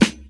• Old School Funk Snare Sample G# Key 341.wav
Royality free steel snare drum sample tuned to the G# note. Loudest frequency: 1611Hz
old-school-funk-snare-sample-g-sharp-key-341-lO2.wav